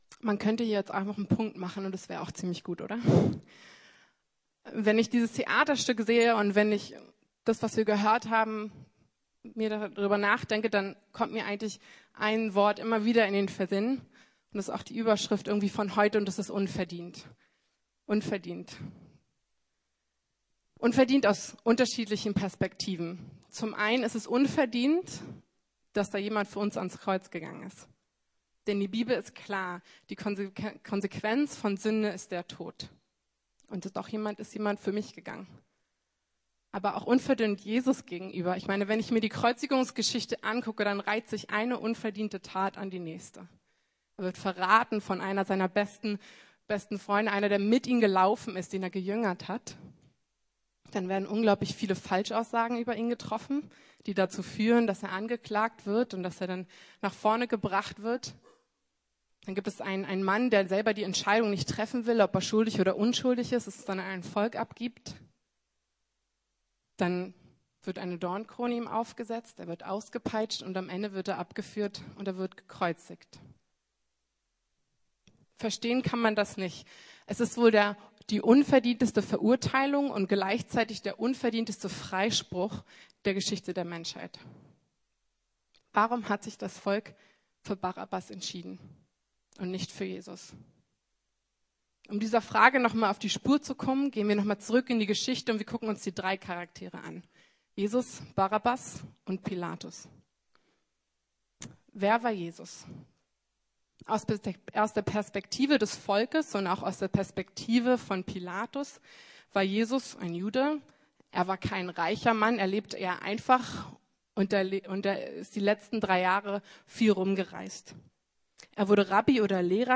Unverdient! ~ Predigten der LUKAS GEMEINDE Podcast